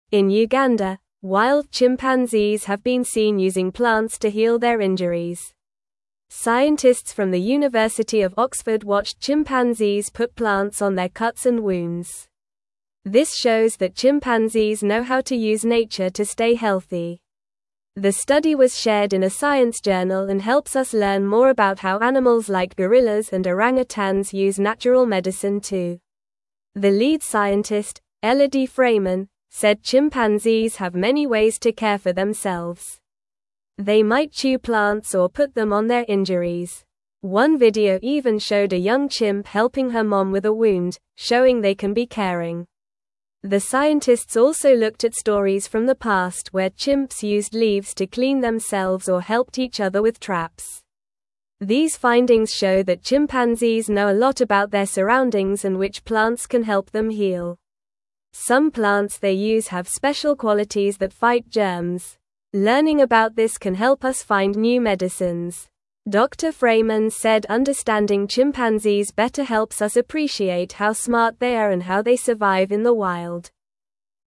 Normal
English-Newsroom-Lower-Intermediate-NORMAL-Reading-Chimps-Use-Plants-to-Heal-Their-Boo-Boos.mp3